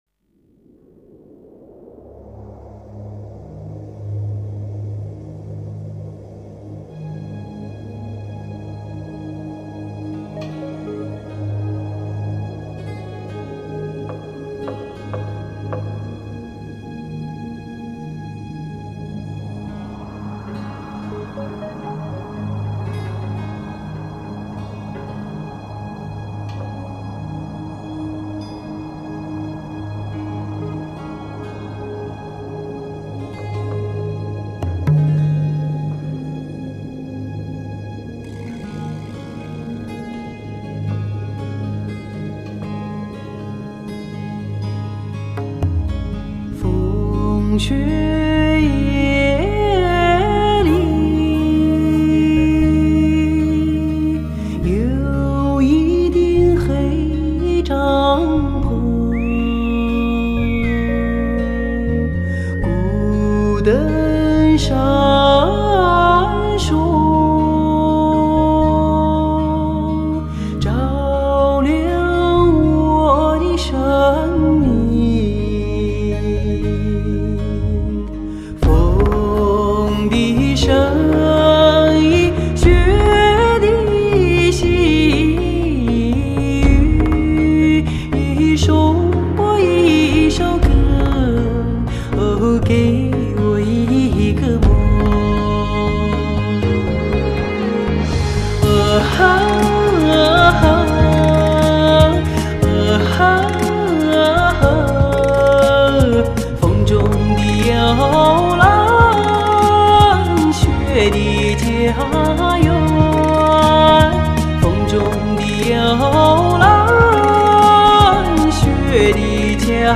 天下最美的女中音
类型: HIFI试音